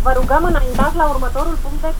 Radio announcer voice effect Free sound effects and audio clips
• drive in voice romanian.wav
Recorded with a Steinberg Sterling Audio ST66 Tube, in a small apartment studio.
drive_in_voice_romanian_FiX_Nkt.wav